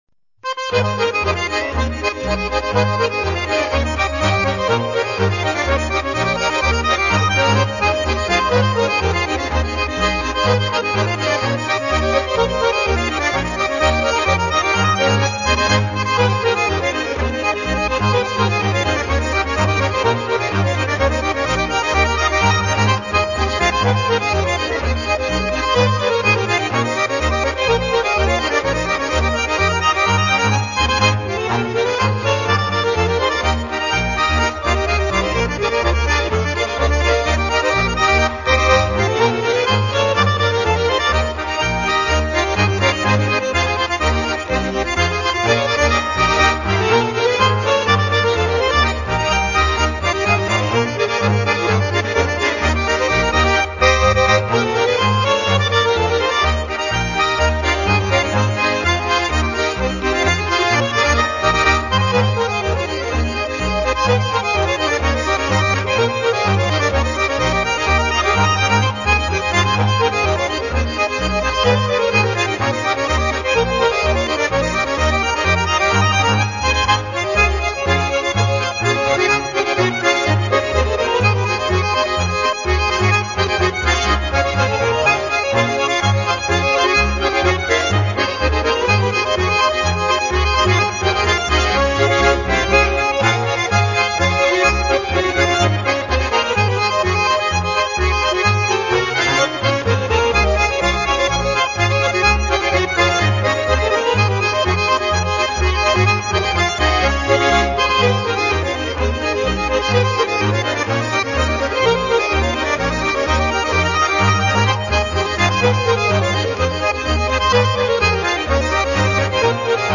Schottisch